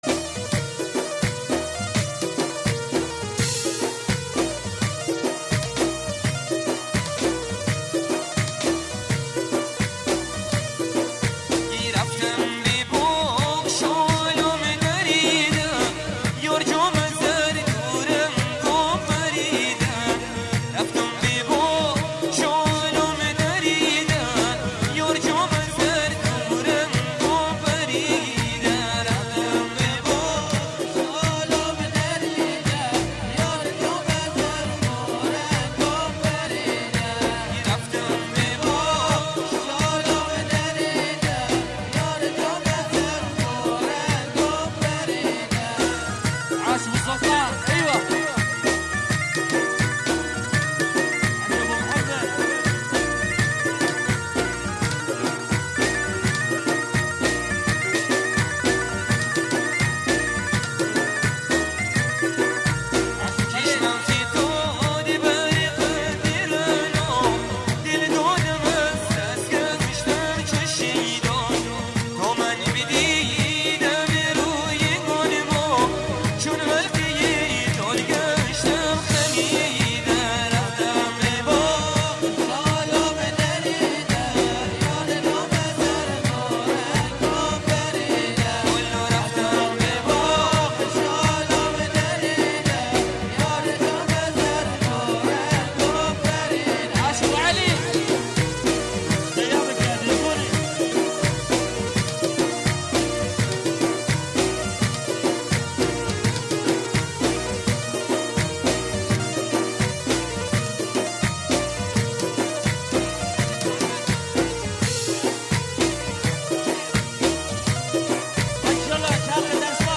اهنگ بندری